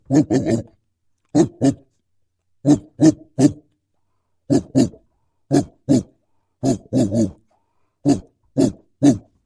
Мультяшный звук с лаем бульдога